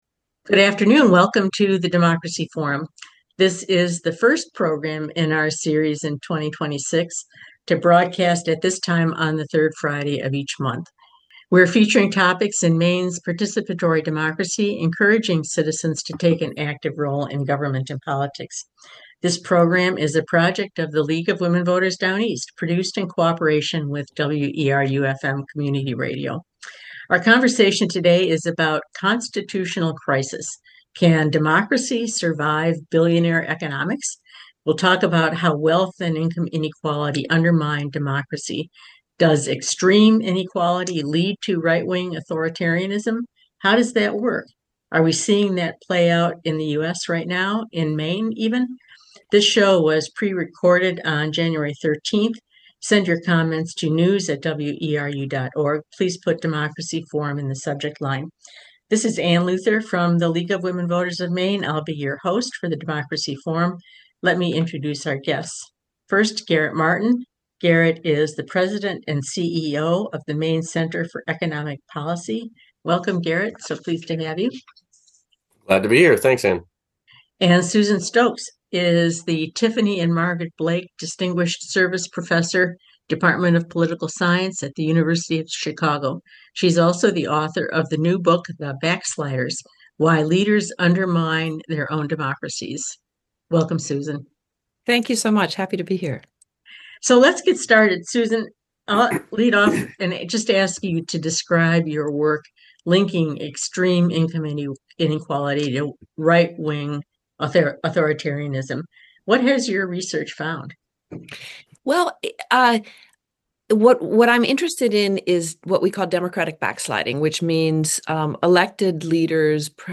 Author: WERU-FM Language: en-us Genres: News Contact email: Get it Feed URL: Get it iTunes ID: Get it Get all podcast data Listen Now...